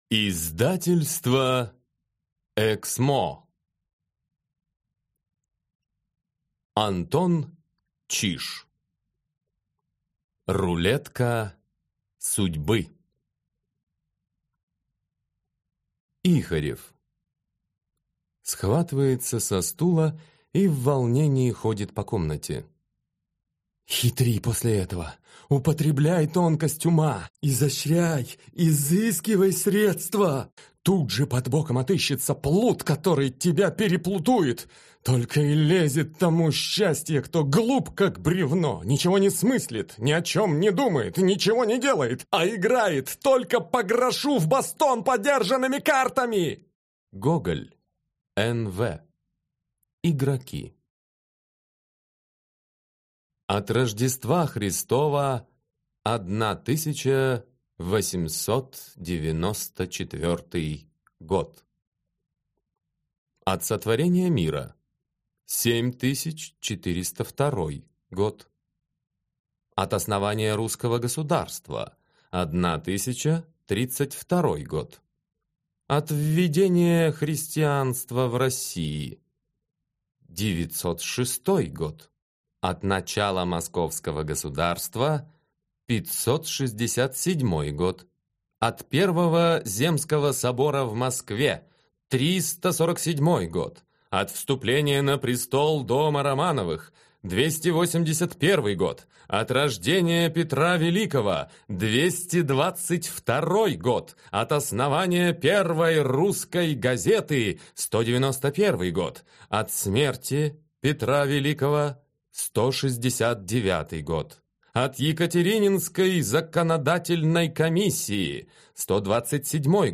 Аудиокнига Рулетка судьбы | Библиотека аудиокниг